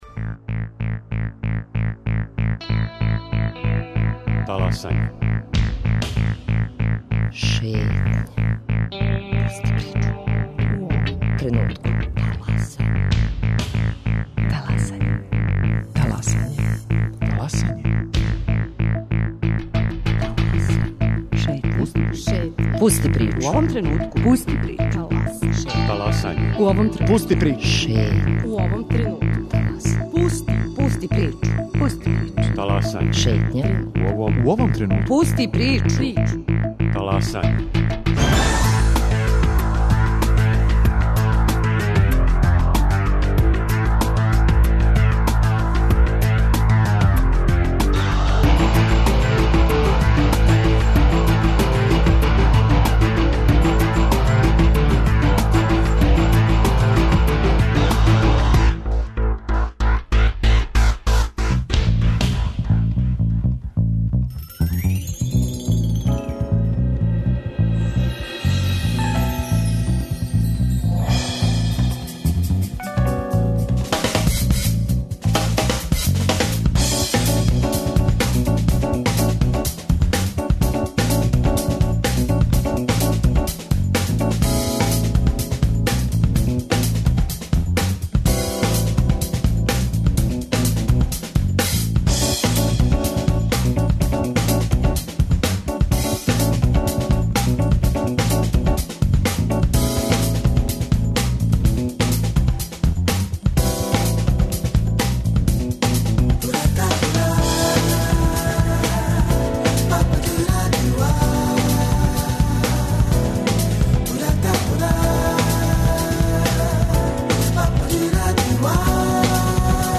Чућемо и наше репортере са Косова и Метохије.